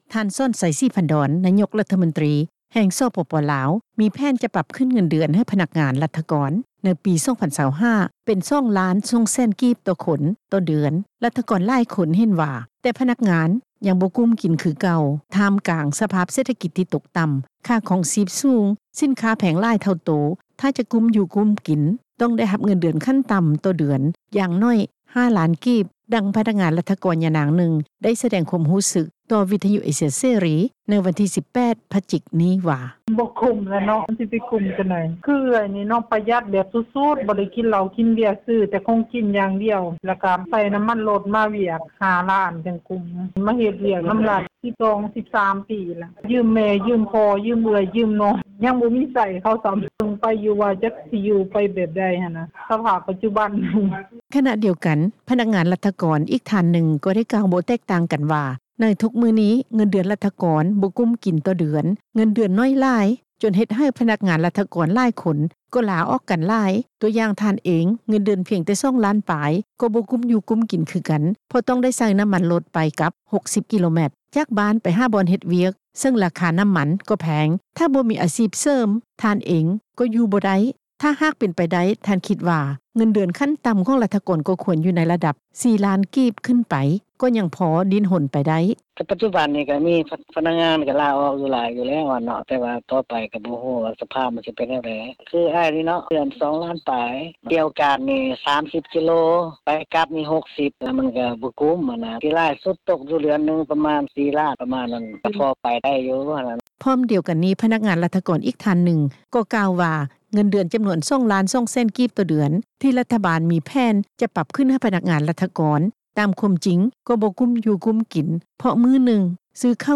ດັ່ງ ພະນັກງານລັດຖະກອນ ຍານາງນຶ່ງ ໄດ້ສະແດງຄວາມຮູ້ສຶກ ຕໍ່ວິທຍຸເອເຊັຽເສຣີ ໃນວັນທີ 18 ພະຈິກ ນີ້ວ່າ: